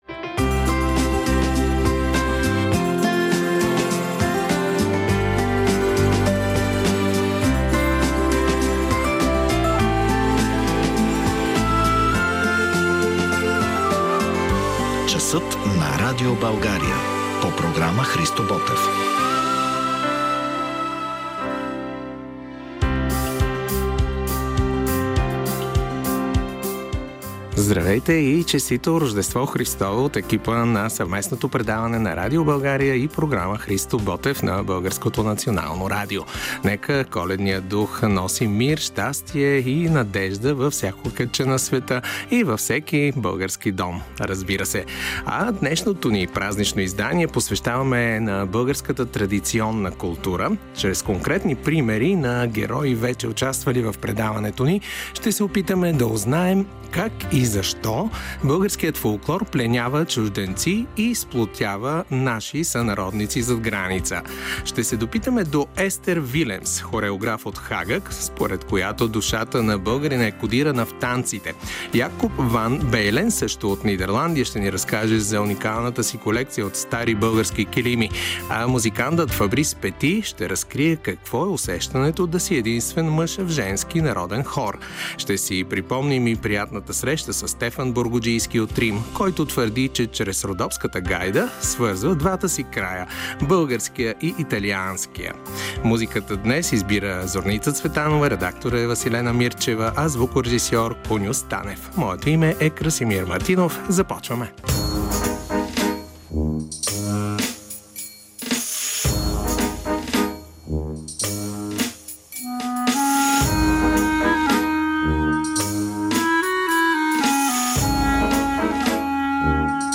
Опитваме се да узнаем как българската традиционна култура пленява чужденци и сплотява българи зад граница с избрани репортажи от годината: